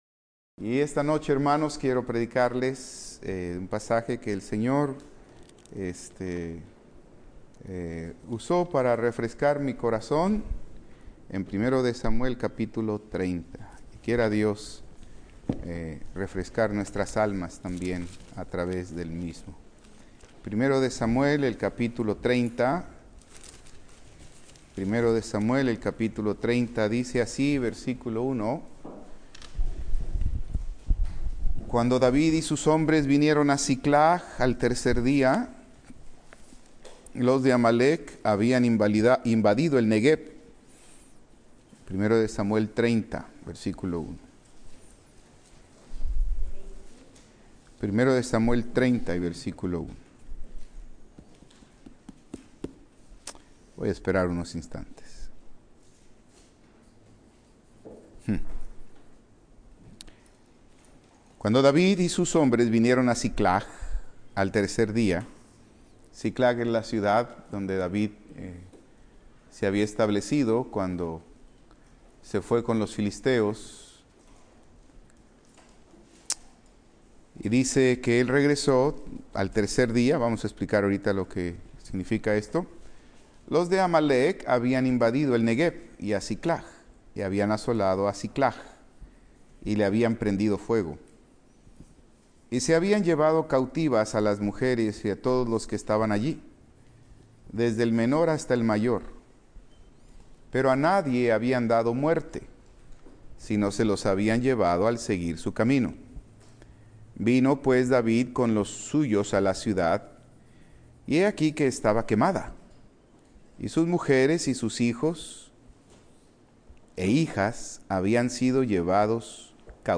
Servicio vespertino